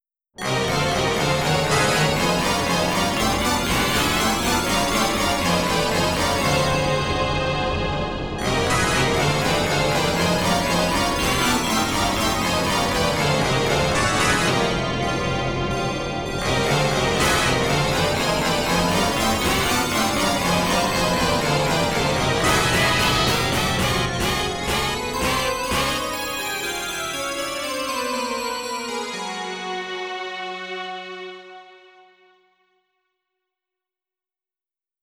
Agitation, Theater Music